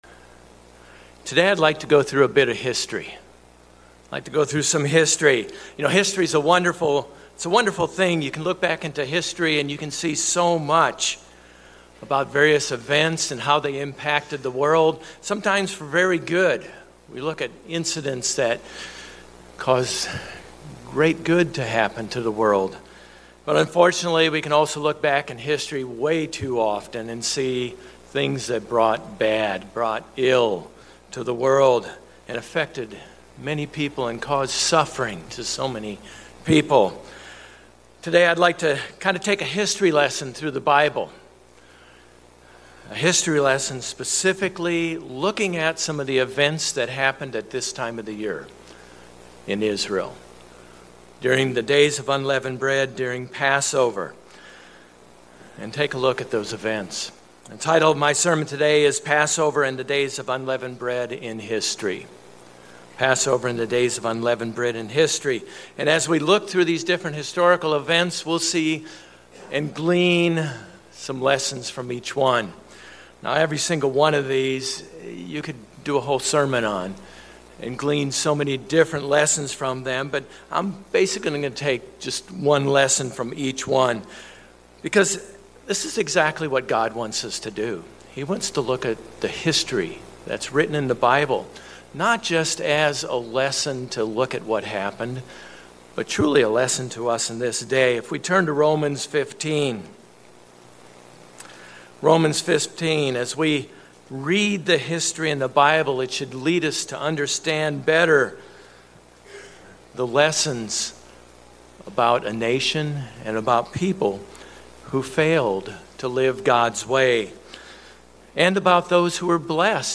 Sermons
Given in Orlando, FL